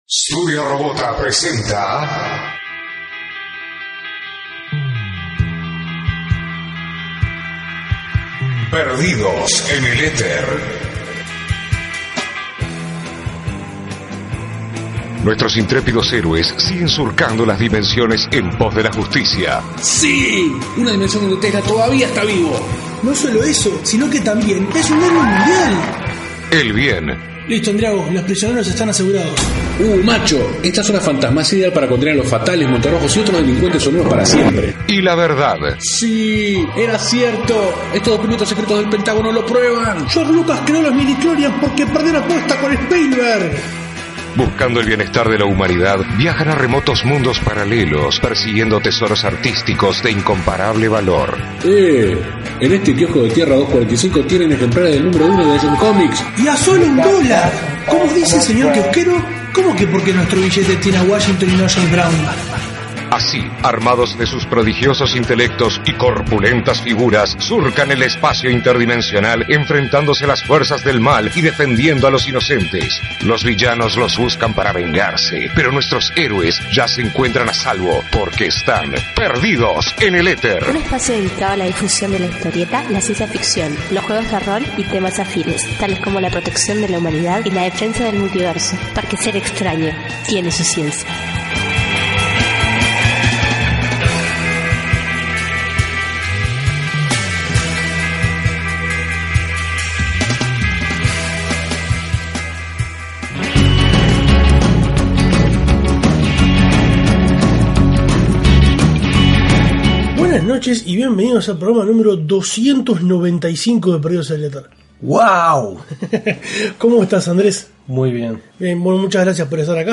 Fuimos a ver la más reciente película de mutantes, y por supuesto, la RE: señamos. Tanto, que la grabamos inmediatamente después de volver del cine.